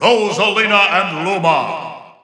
The announcer saying Rosalina & Luma's names in English releases of Super Smash Bros. 4 and Super Smash Bros. Ultimate.
Rosalina_&_Luma_English_Announcer_SSB4-SSBU.wav